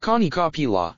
Kanikapila is pronounced “Kah knee kah pe La”.
kanikapila.mp3